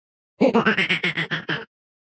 Giggle Sound Effects MP3 Download Free - Quick Sounds